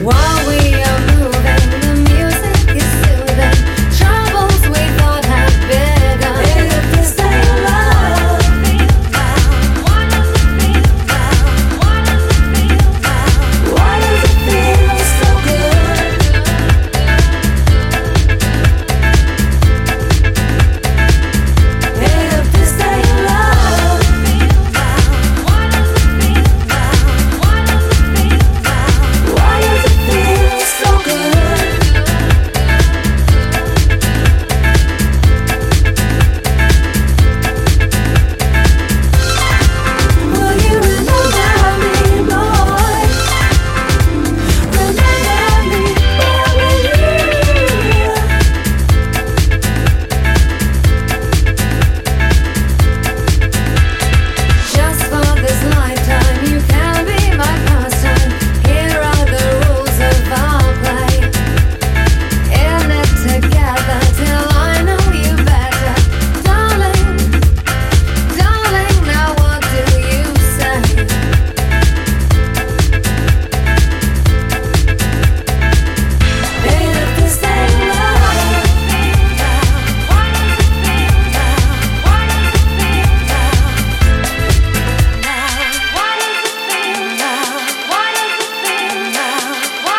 ジャンル(スタイル) DISCO HOUSE / DEEP HOUSE / POP